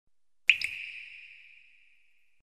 waterdrop.mp3